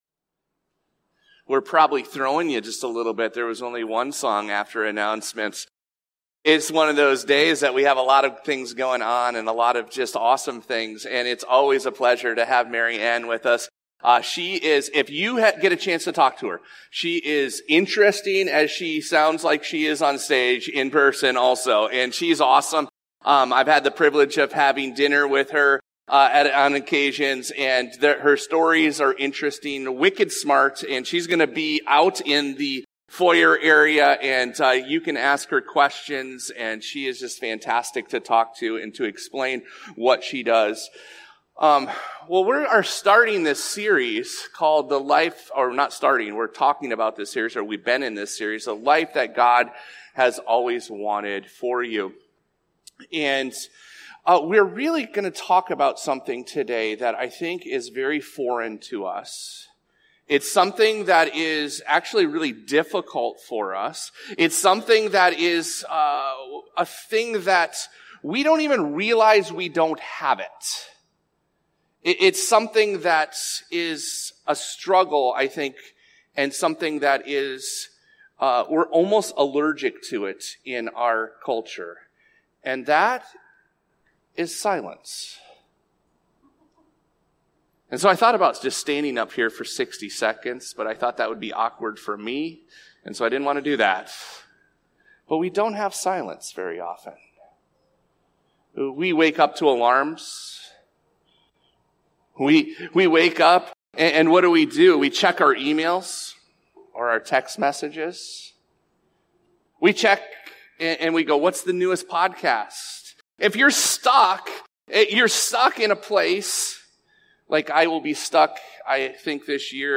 This podcast episode is a Sunday message from Evangel Community Church, Houghton, Michigan, July 13, 2025.